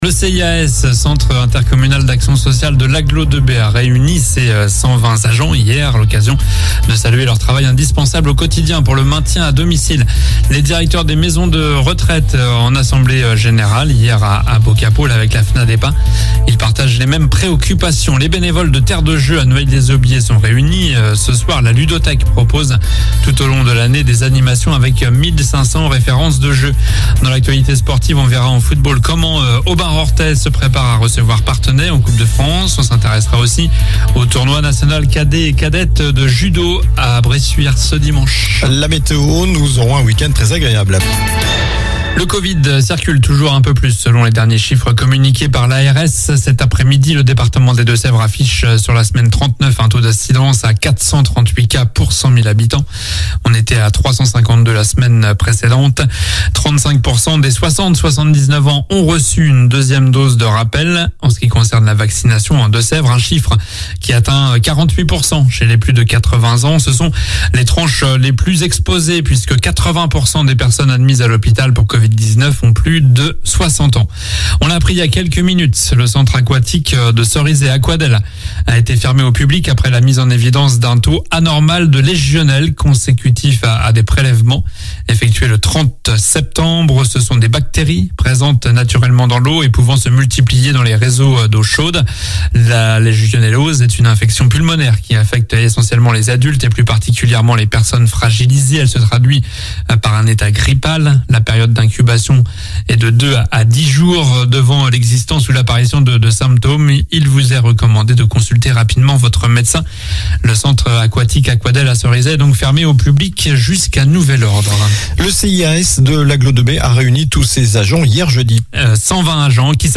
Journal du vendredi 07 octobre (soir)